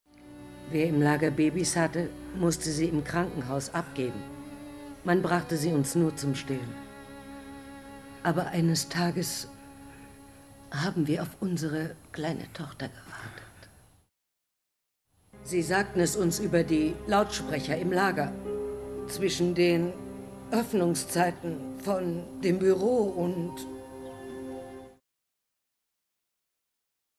Synchronschauspielerin
Hier ein paar SPRACHPROBEN